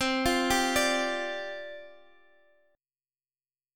Listen to Csus2sus4 strummed